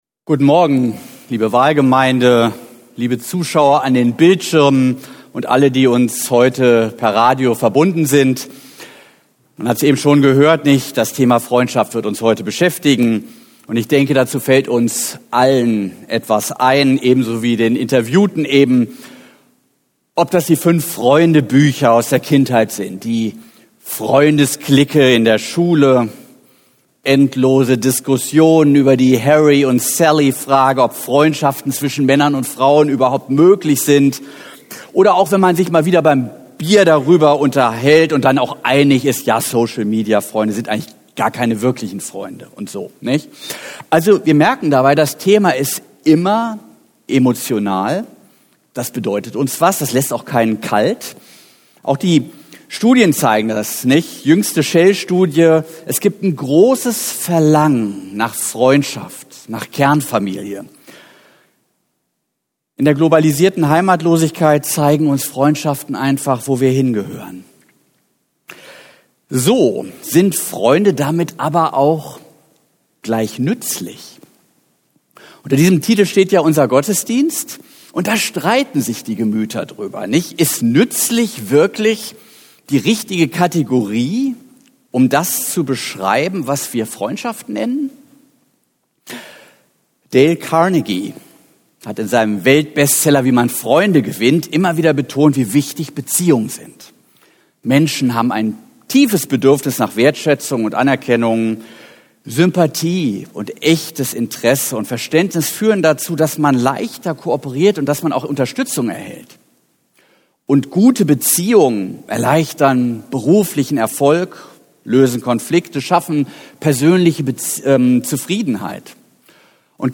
Was nützen mir schon Freunde … ? TV-Gottesdienst ~ PODWalCAST Podcast